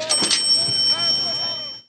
• CASH REGISTER DING.wav
CASH_REGISTER_DING_QjL.wav